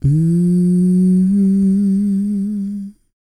E-CROON P301.wav